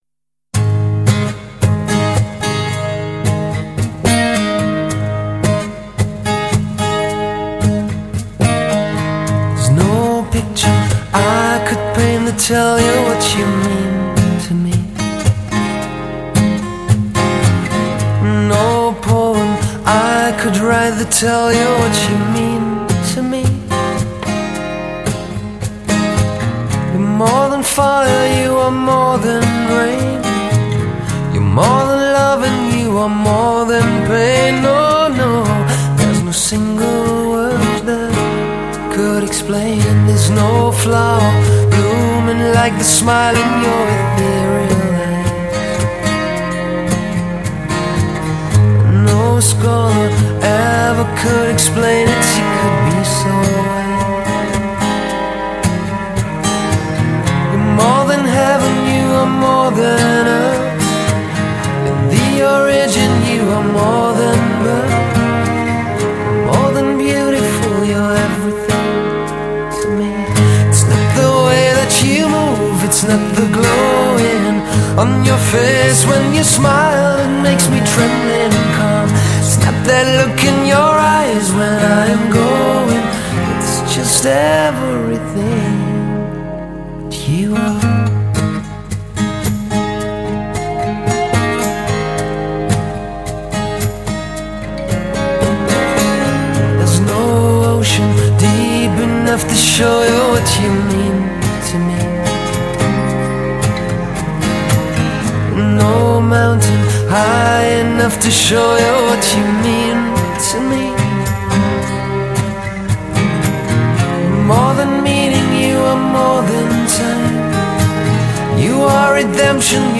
与以前的唱片相同，这张新专辑也充满了六、七十 年代的音乐感觉，或畅快、或浓郁，皆是真性情的自然流露。
合唱、伴唱的大量运用使之带有明显的表 演色彩，感染力很强。